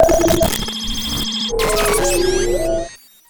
Звуки помех, глитча
Звук цифровых помех инопланетян